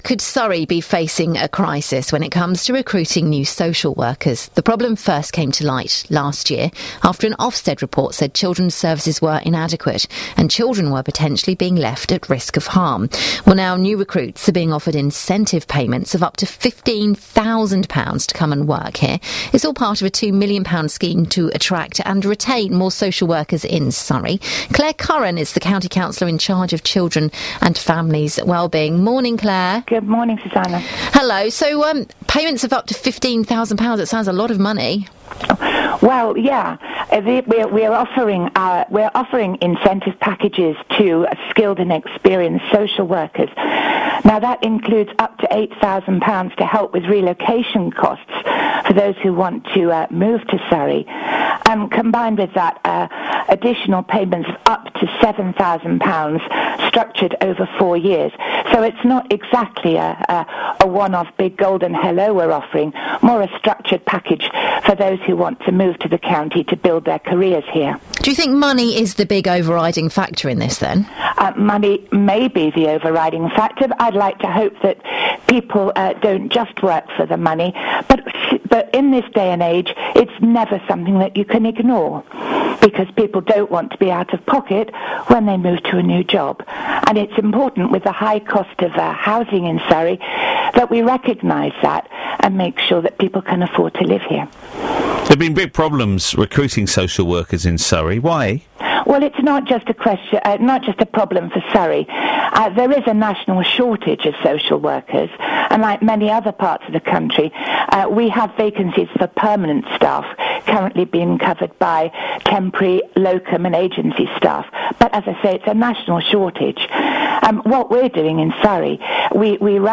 AUDIO: BBC Surrey interview with Clare Curran about drive to recruit social workers